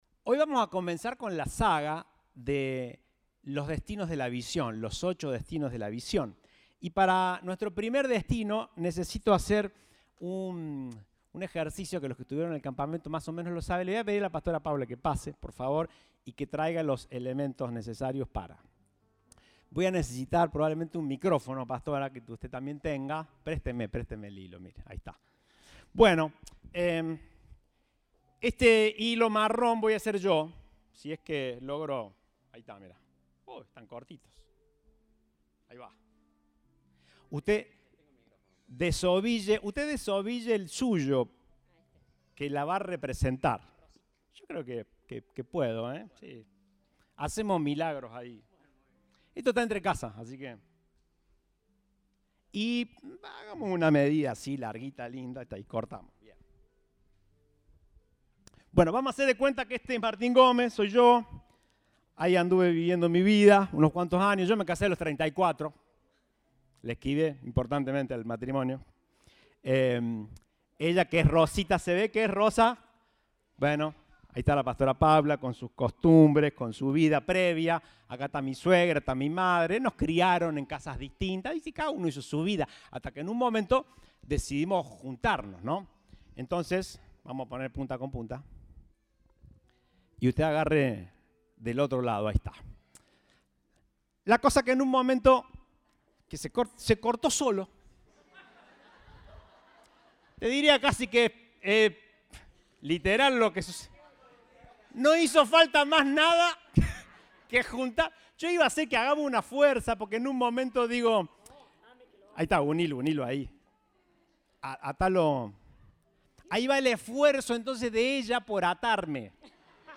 Compartimos el mensaje del Domingo 3 de Abril de 2022.